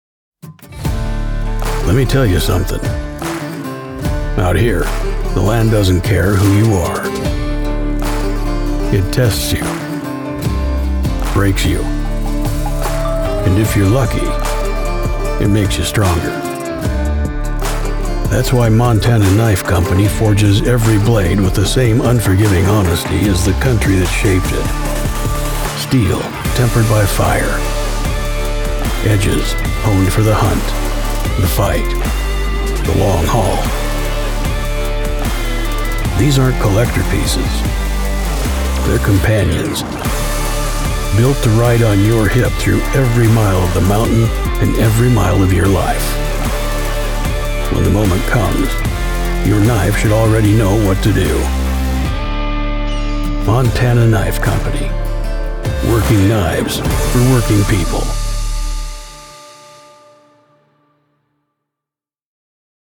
Rugged. Resonant. Real.
✔ Distinctive Grit
Gritty Voice Over Demo
A gritty voice over features a rugged, textured, masculine tone that adds cinematic weight and authenticity to commercials, trailers, and promos.